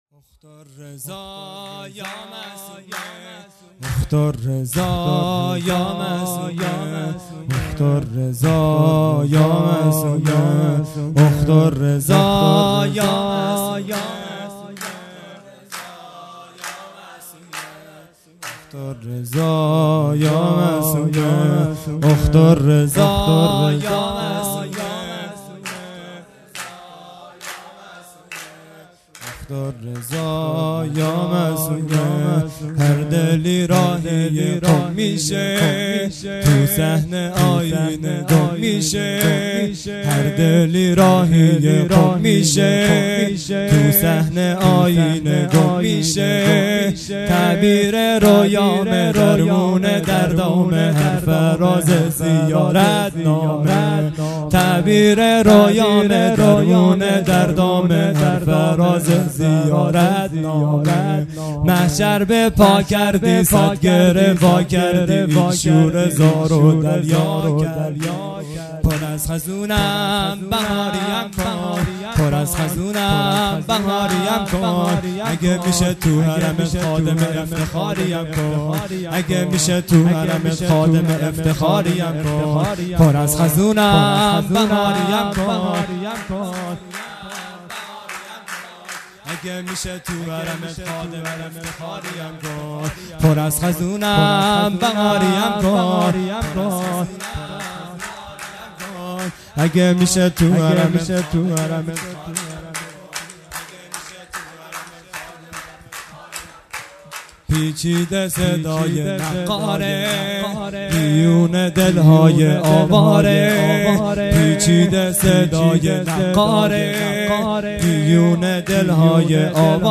خیمه گاه - هیئت بچه های فاطمه (س) - سرود | اخت الرضا یا معصومه
جلسه هفتگی هیئت به مناسبت ولادت حضرت معصومه(س)